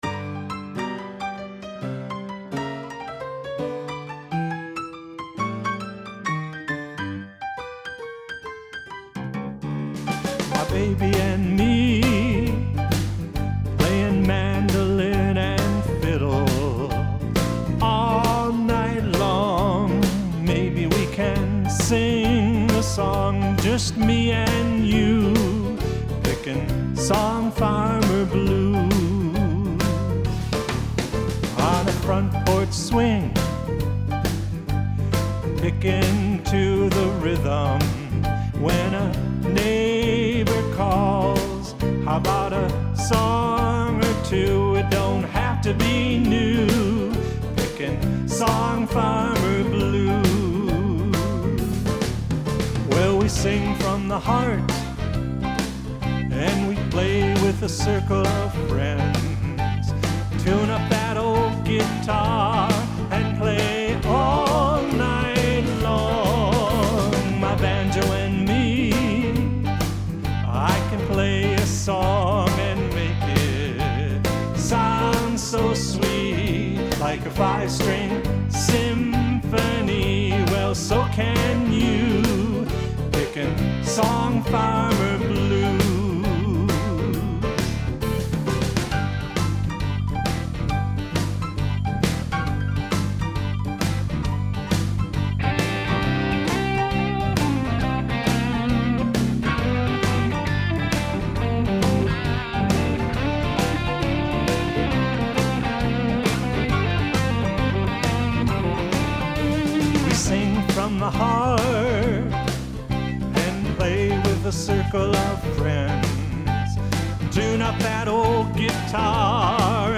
a rocking jazz piano rendition